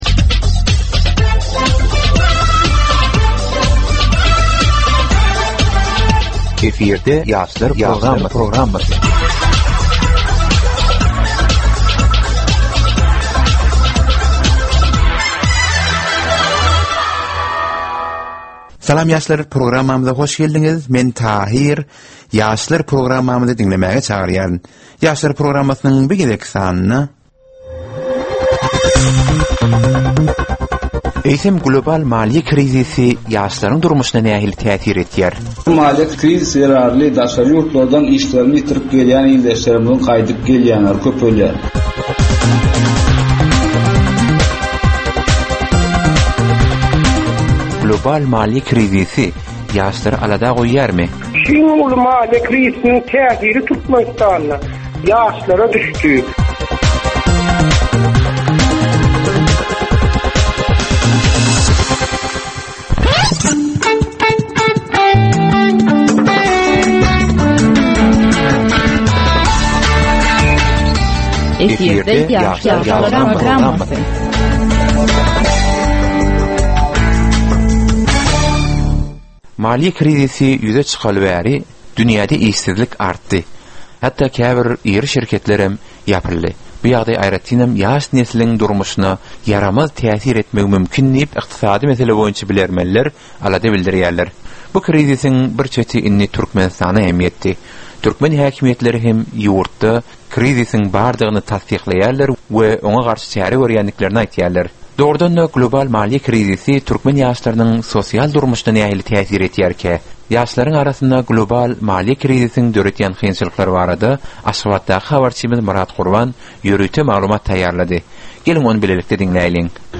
Geplesigin dowmynda aýdym-sazlar hem esitdirilýär.